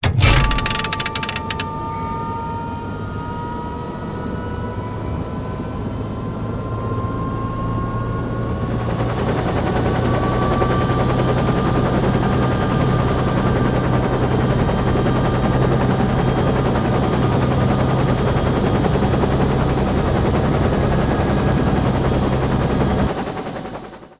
takeoff.wav